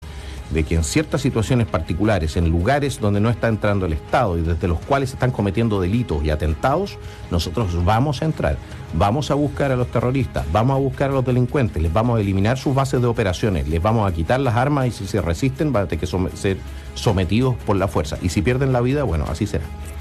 Acompañado de adherentes y en medio de la plaza Aníbal Pinto de Temuco, región de La Araucanía, el candidato presidencial del Partido Nacional Libertario, Johannes Kaiser, respondió las preguntas de la ciudadanía que plantearon sus inquietudes.